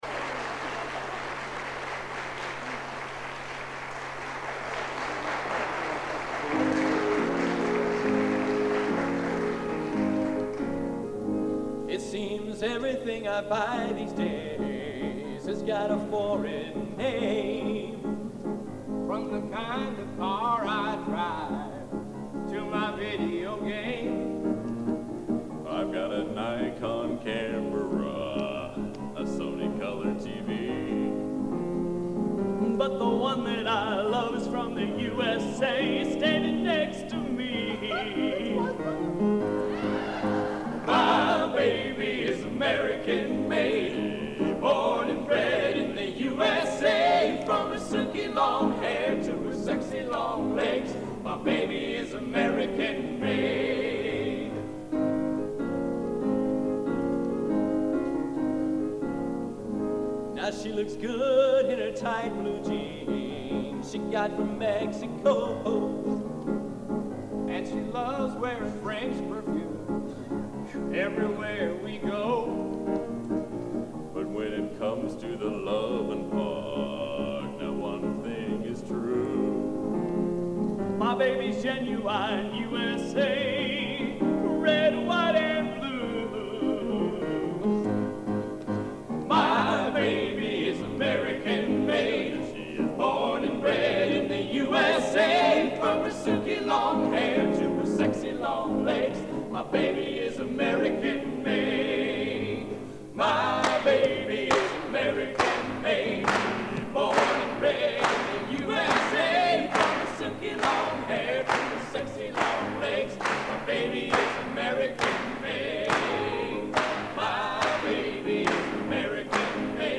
Location: Old Academy of Music, Stockholm, Sweden